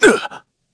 Shakmeh-Vox_Damage_kr_01.wav